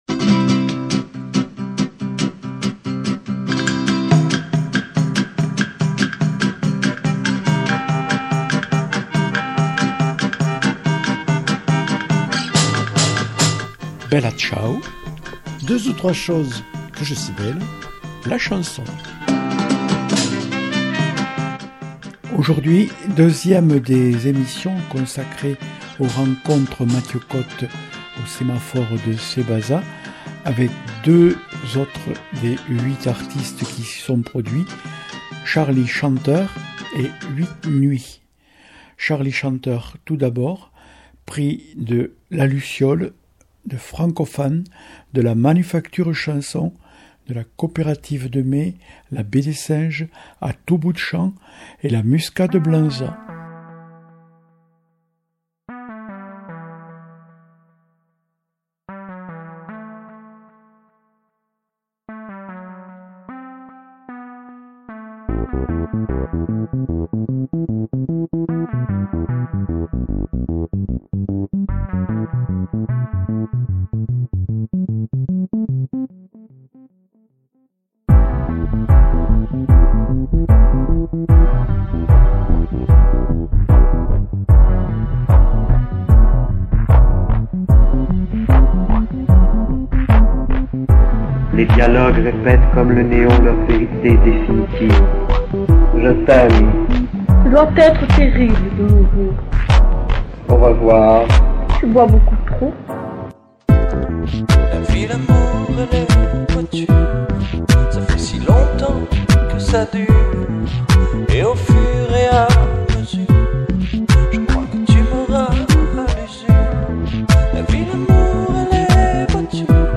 Suite du feuilleton de 4 émissions enregistrées au Sémaphore de Cébazat (63) en novembre où dans chacun des numéros nous faisons connaissance avec deux des huit artistes finalistes.